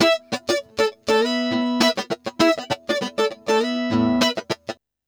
100FUNKY09-L.wav